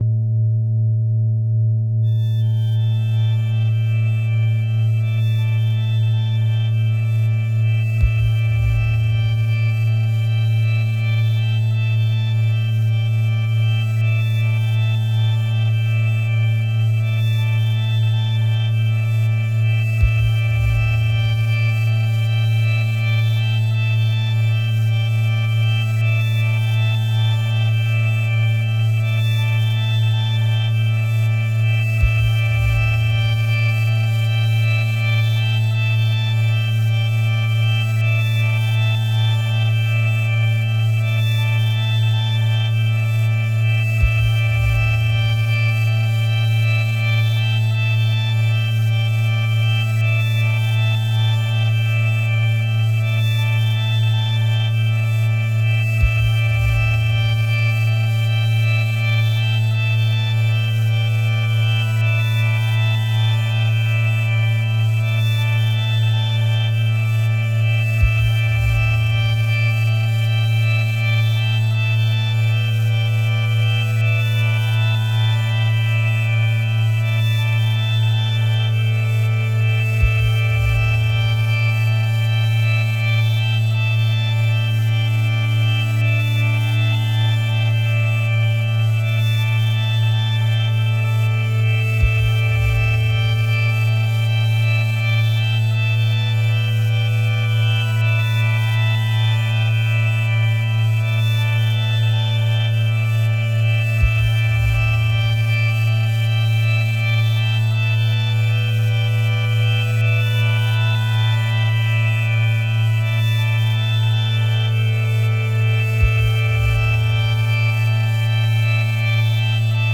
psychedelic drone rock